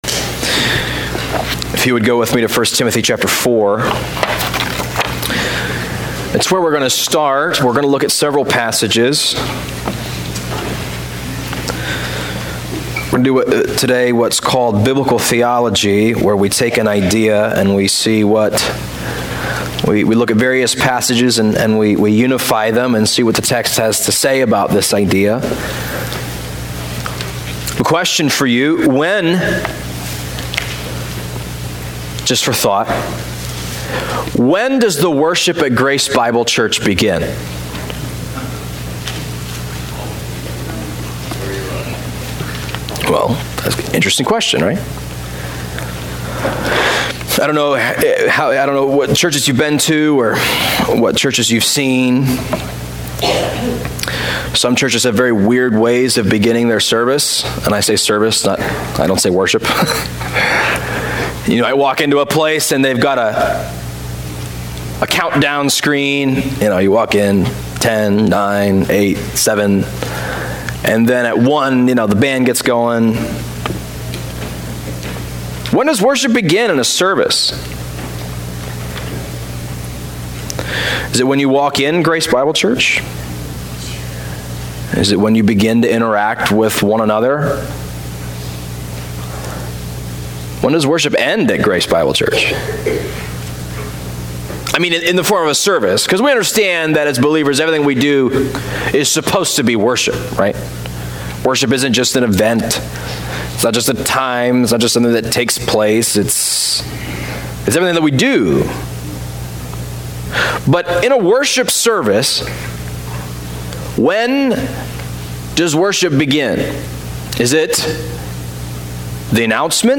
A Service Snapshot: Scripture Reading (10-13-19)